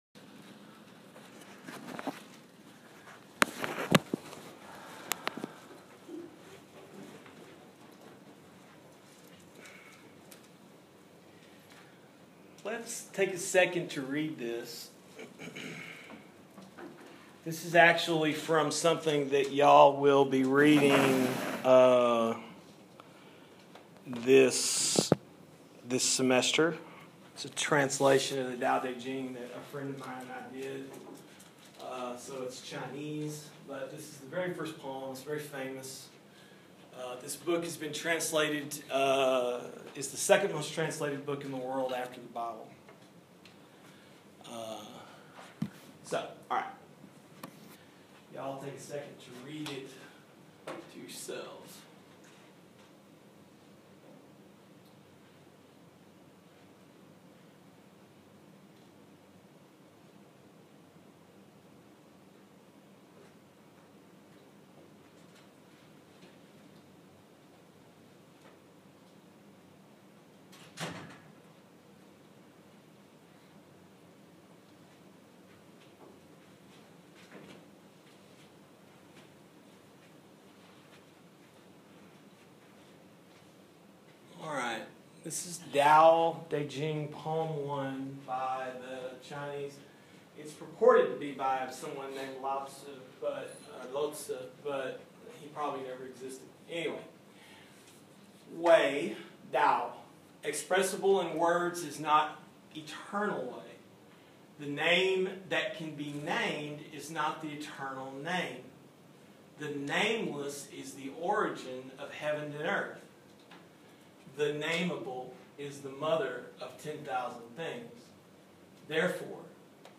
Lecture from Sacred Living Traditions.